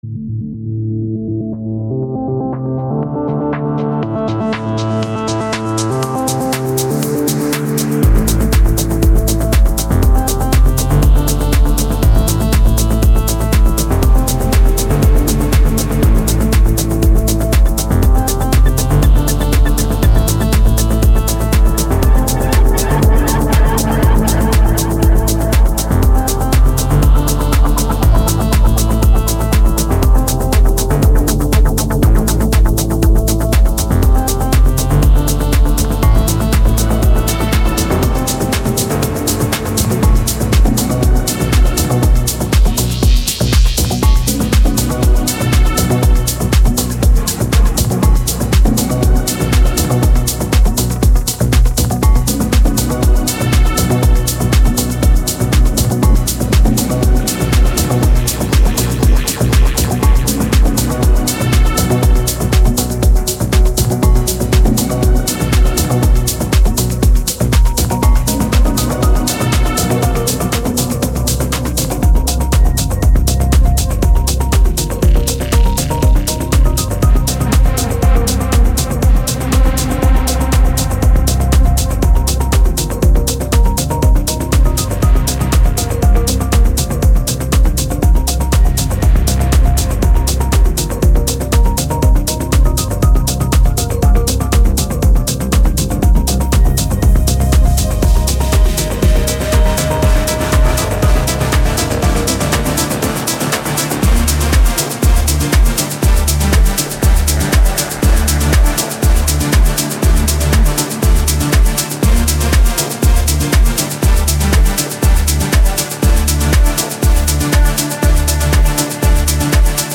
Genre:Progressive House
名前が示す通り、夜通し踊るのにぴったりのサンプルパックです。
デモサウンドはコチラ↓